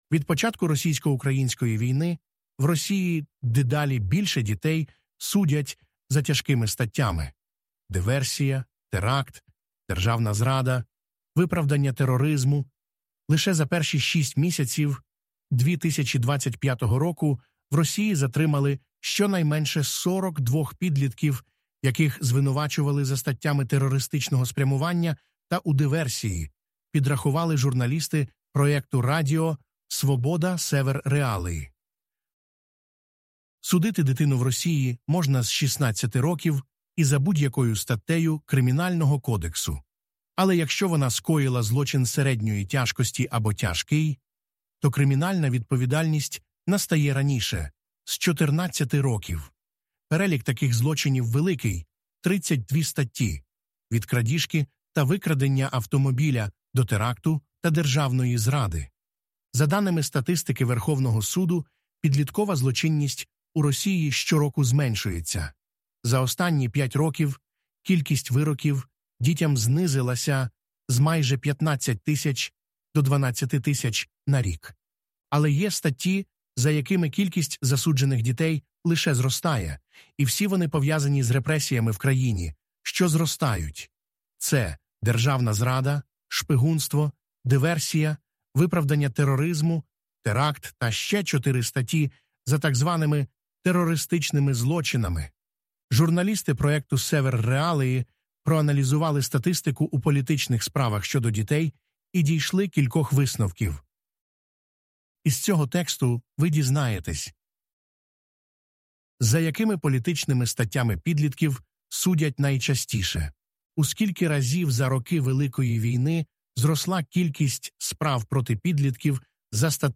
Це аудіо озвучено з допомогою штучного інтелекту